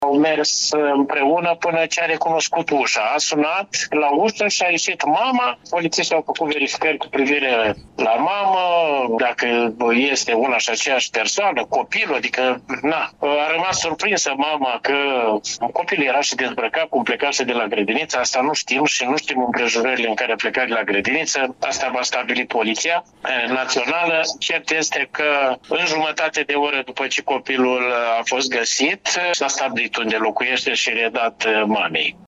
Comandantul Poliției Locale Iași, Liviu Zanfirescu, a declarat că minorul era îmbrăcat sumar, în trening, deși afară era ger.